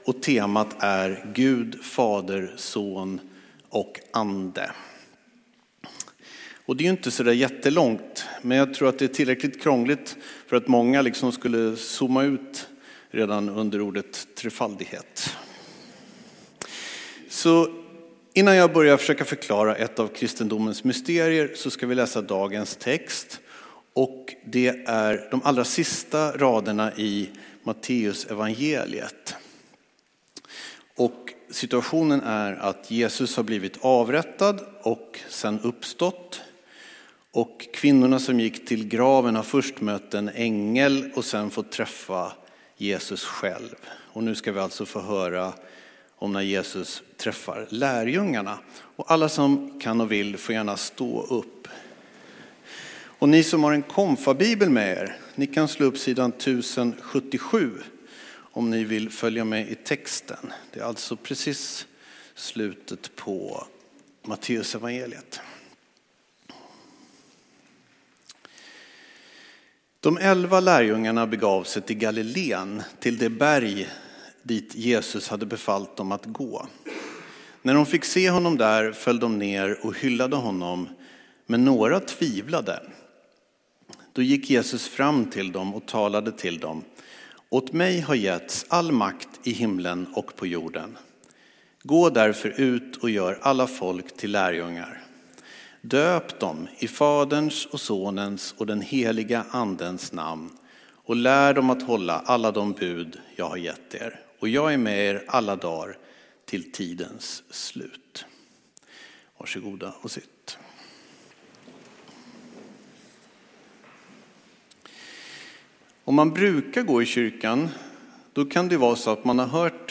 Här hittar du inspelningar från gudstjänster och andra tillställningar i Abrahamsbergskyrkan i Bromma i Stockholm. Av upphovsrättsliga skäl rör det sig huvudsakligen om predikningar.